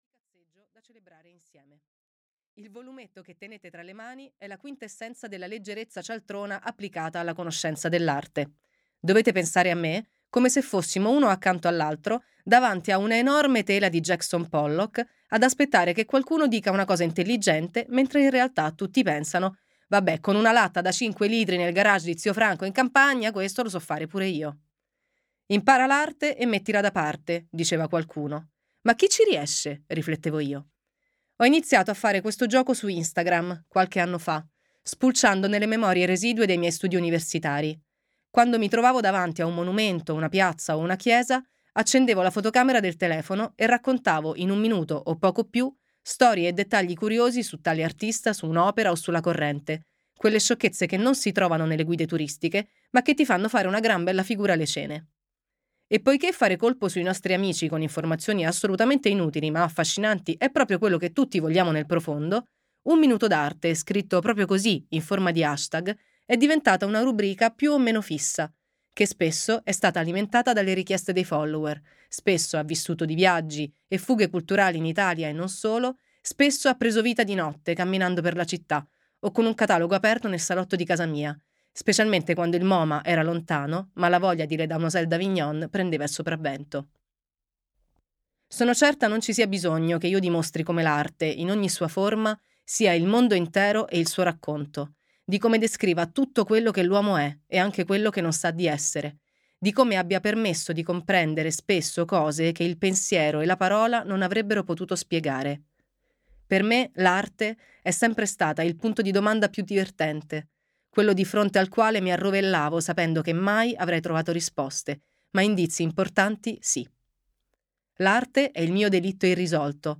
"Un minuto d'arte" di Daniela Collu - Audiolibro digitale - AUDIOLIBRI LIQUIDI - Il Libraio
• Letto da: Daniela Collu